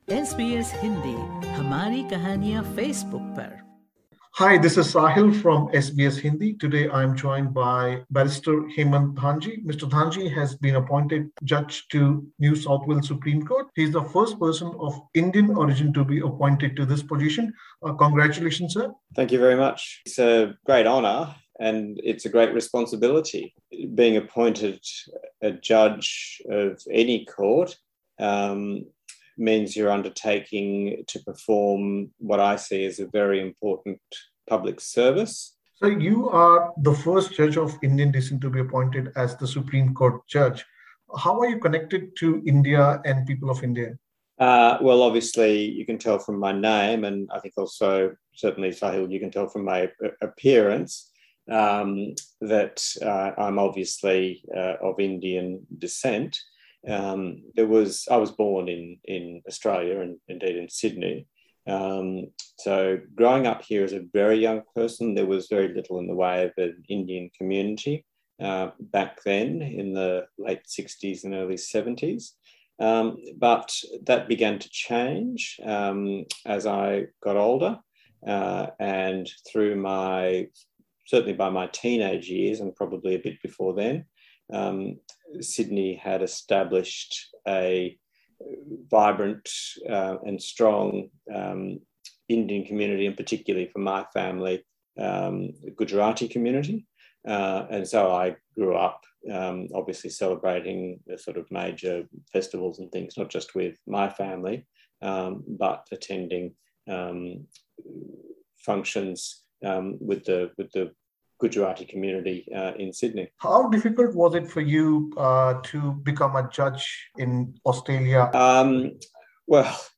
dhanji_interview.mp3